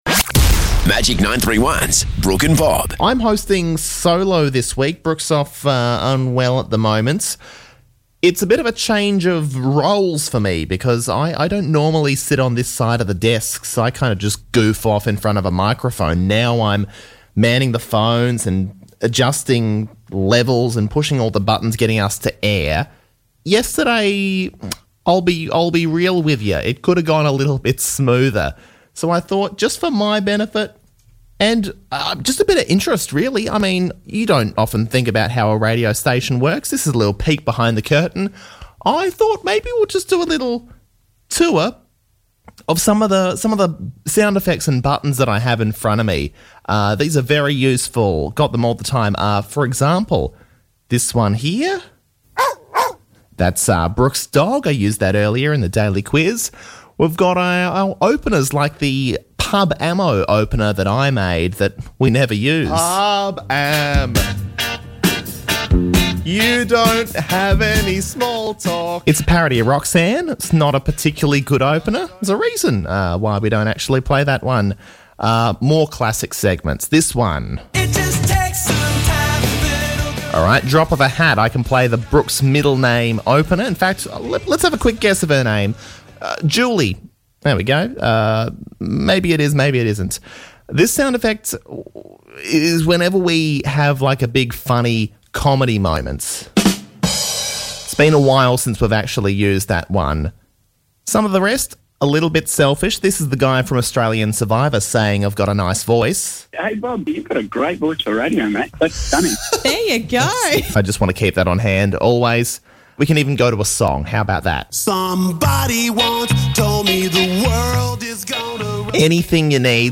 whilst hosting the show solo, so he took the time to familiraise himself with all our sound effects.